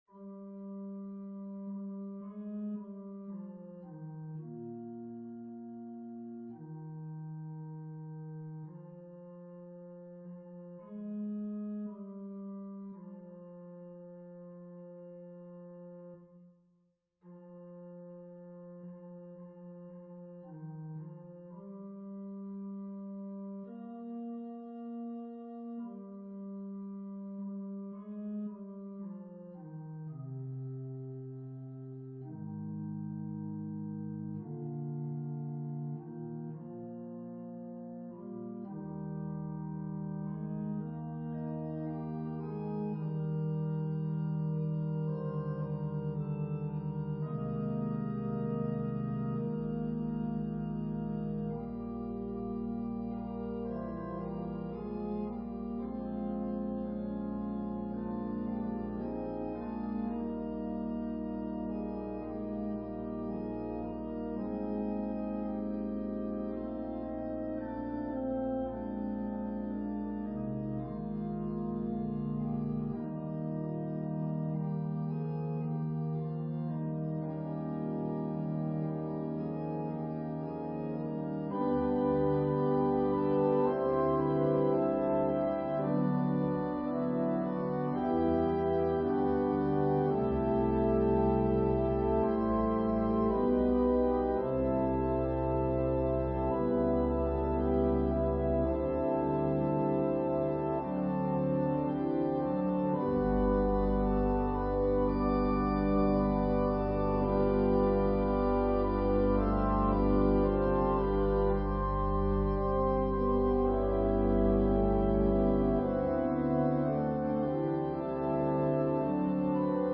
An organ solo version of Mack Wilberg's choral arrangement.
Voicing/Instrumentation: Organ/Organ Accompaniment We also have other 30 arrangements of " Did You Think to Pray?